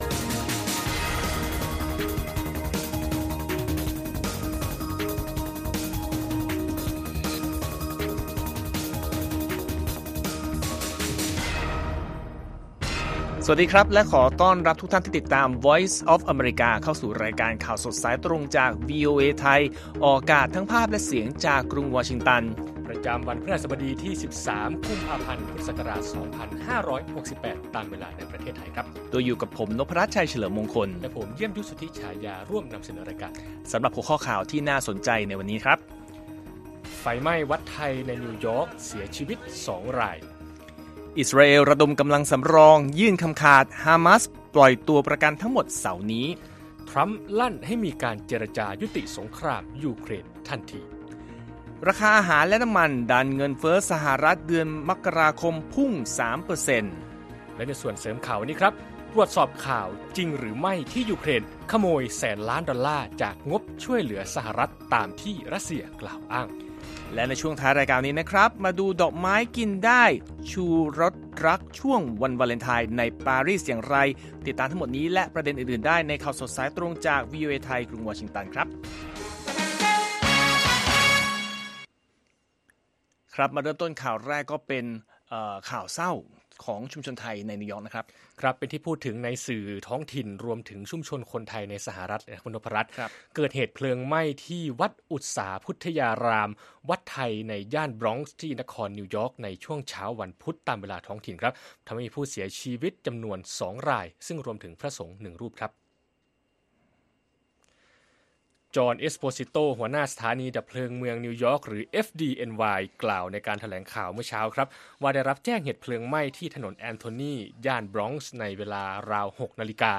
ข่าวสดสายตรงจากวีโอเอไทย วันพฤหัสบดี ที่ 13 ก.พ. 2568